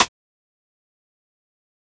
Snare (SizzleLife4).wav